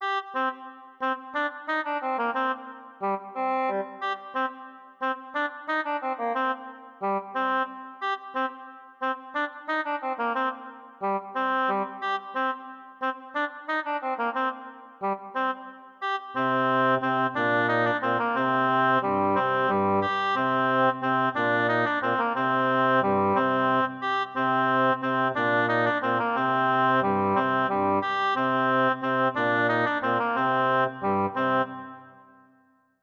Música ambiental del cuento: La princesa Ranita
ambiente
melodía
sintonía